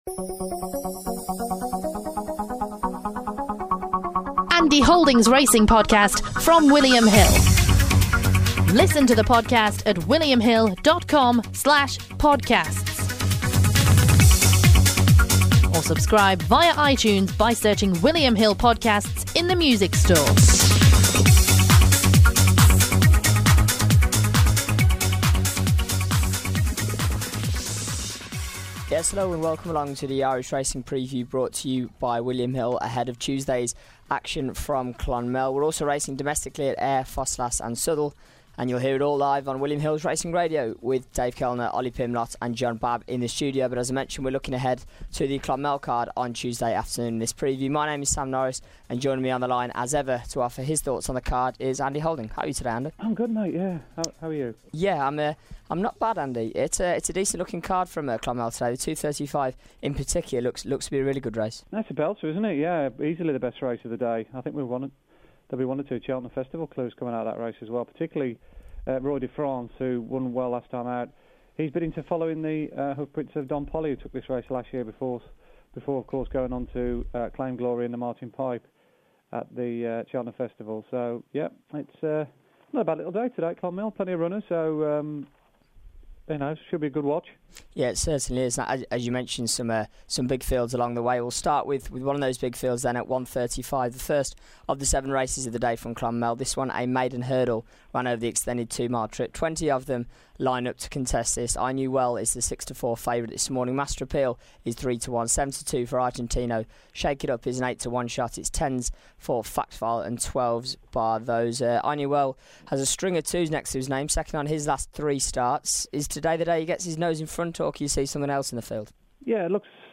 on the line to preview every race on the card and provide his selections.